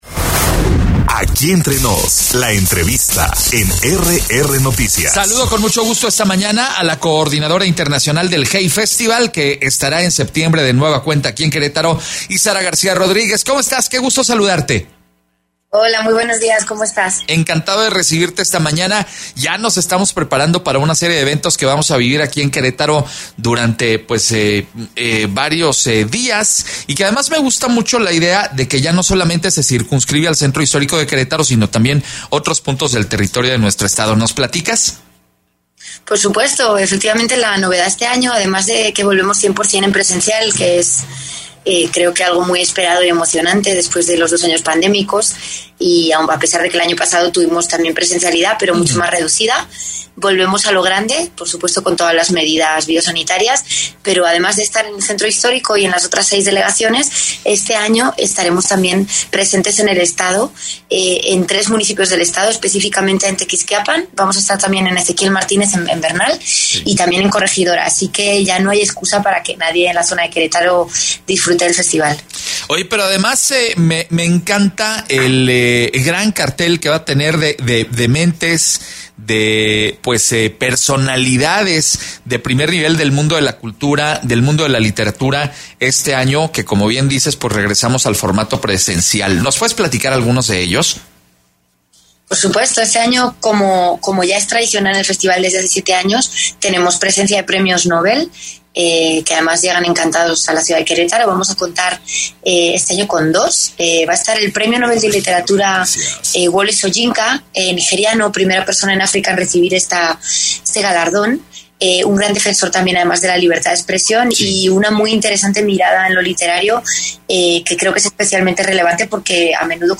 EntrevistasPodcast